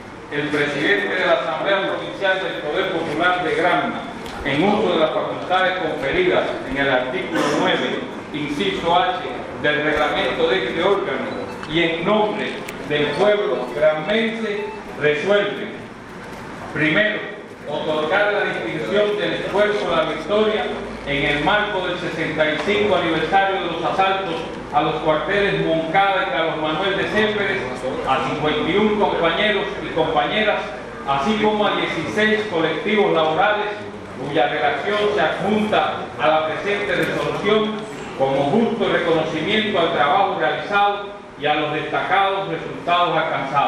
La Distinción Del esfuerzo la victoria, que otorga la Asamblea provincial del Poder Popular (APPP), en Granma, fue impuesta a 51 personas naturales y 16 jurídicas, en ceremonia realizada esta tarde en el Salón de protocolo de la Plaza de la Patria, en Bayamo.